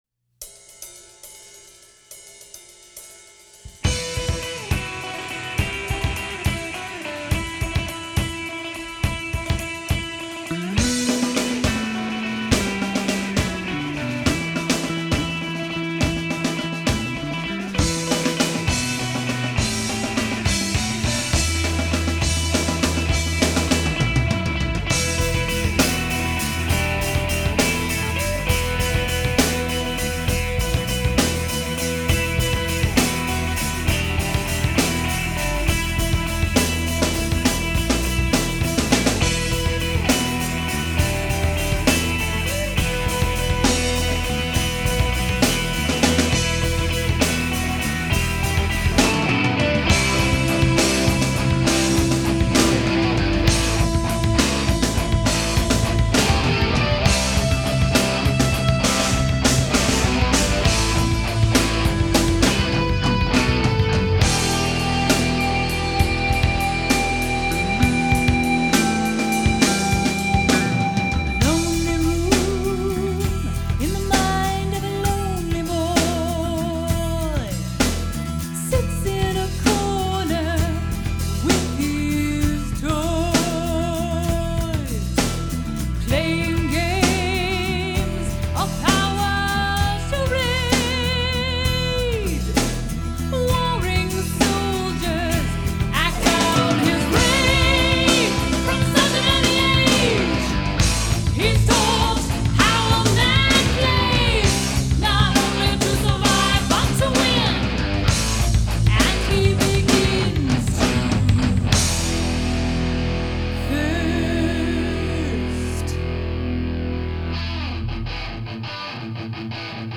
Song recorded in 1993 by our band at the time, Medicine Hat.
I'm playing bass
guitar
vocals
drums